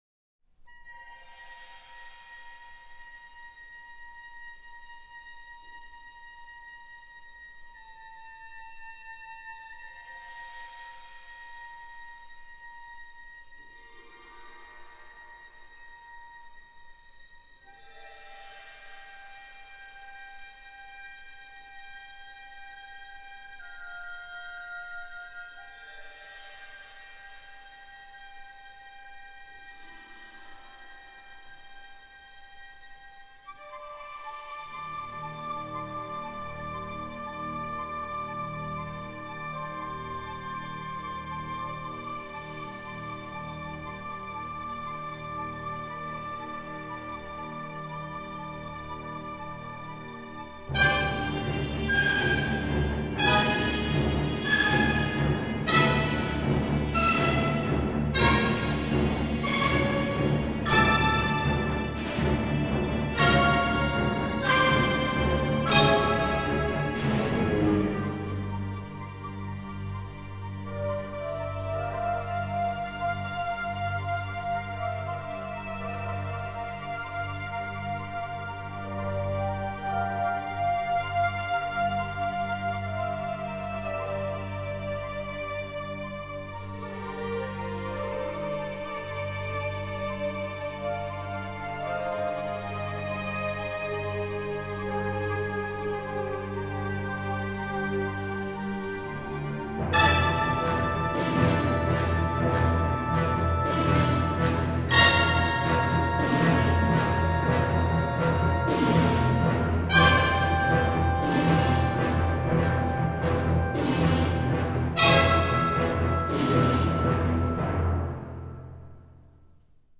Genre: Classical, Stage & Screen
Style: Soundtrack, Score, Neo-Classical, Contemporary